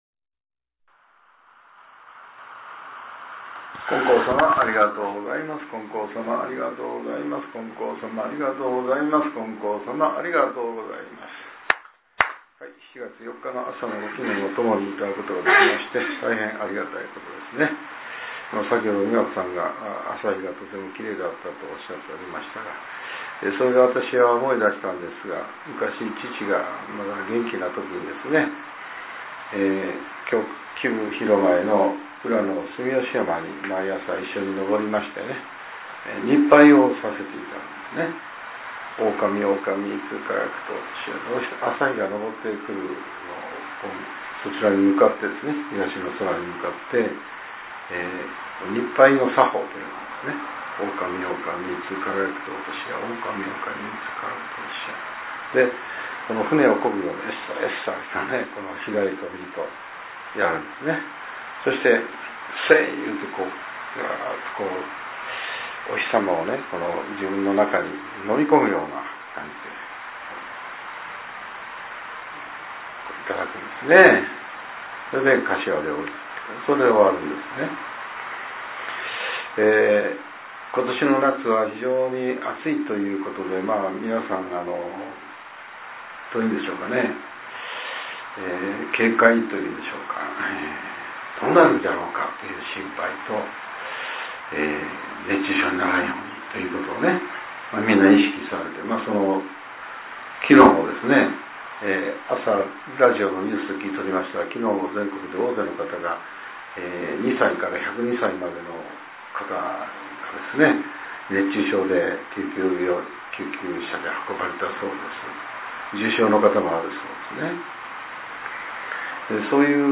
令和７年７月２日（朝）のお話が、音声ブログとして更新させれています。